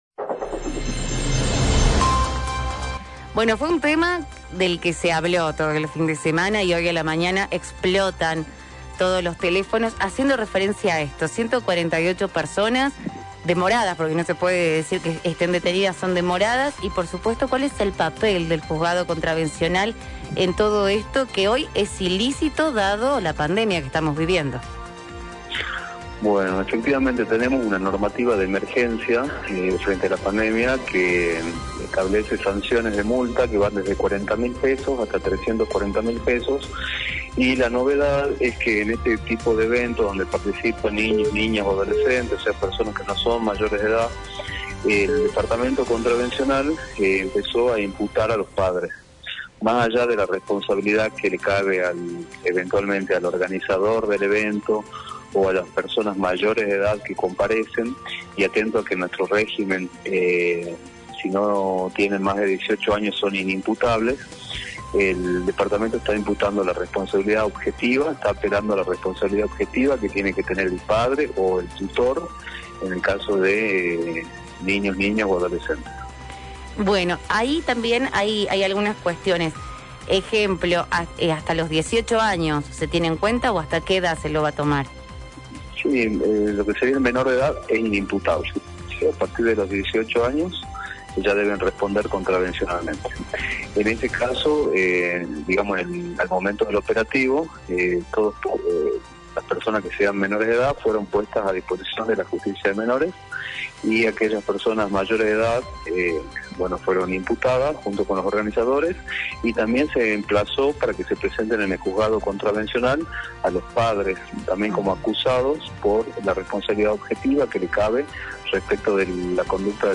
Los tiempos de la justicia en dictar sentencias, multas o sanciones ante estos incumplimientos tendrán relación directa con la voluntad de las personas apuntadas, sin embargo el juez contravencional, Matías Ustárez, aclaró a La Mañana de City que los montos de las multas van de $40 mil a $300 mil, donde se contempla situación económica y posibilidad de la persona.
Matías Ustarez, Juzgado Contravencional: fiestas clandestinas en Jujuy y la responsabilidad de los padres de los menores involucrados a la hora de las multas y sanciones